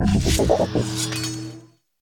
Cri de Scalpereur dans Pokémon Écarlate et Violet.